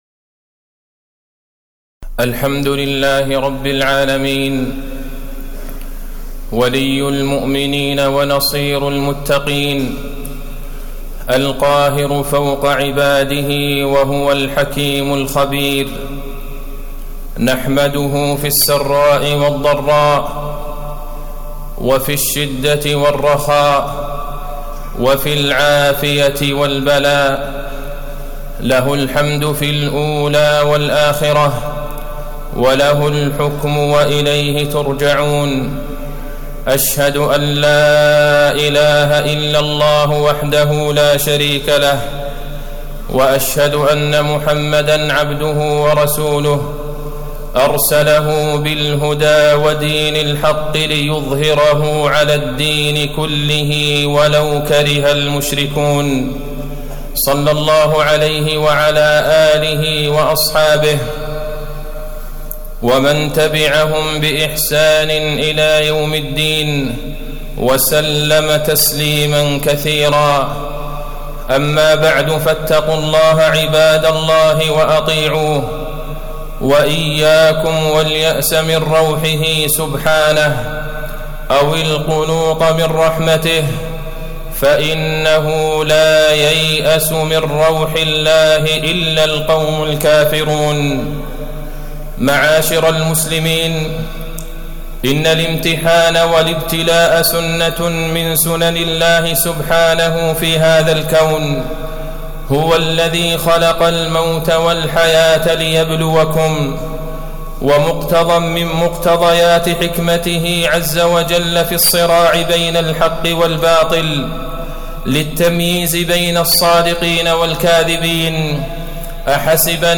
تاريخ النشر ٨ ربيع الثاني ١٤٣٨ هـ المكان: المسجد النبوي الشيخ: فضيلة الشيخ د. عبدالله بن عبدالرحمن البعيجان فضيلة الشيخ د. عبدالله بن عبدالرحمن البعيجان الإيمان وفتنة الابتلاء